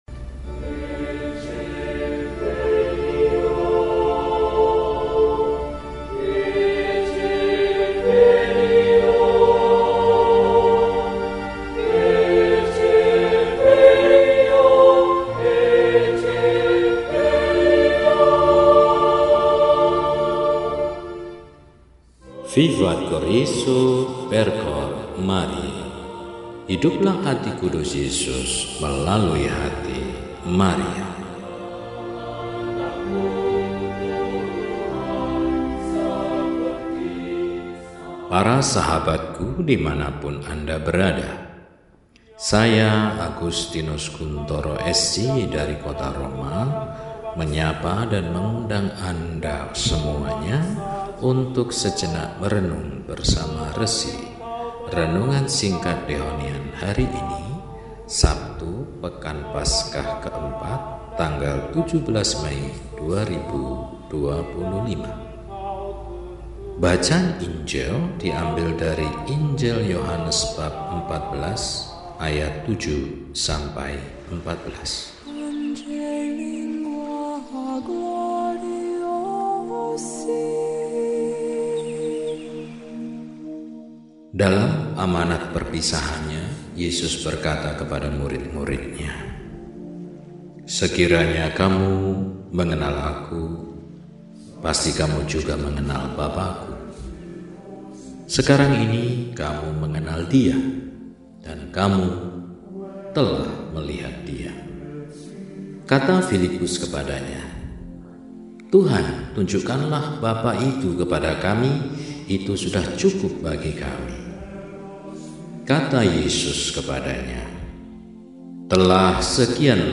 Sabtu, 17 Mei 2025 – Hari Biasa Pekan IV Paskah – RESI (Renungan Singkat) DEHONIAN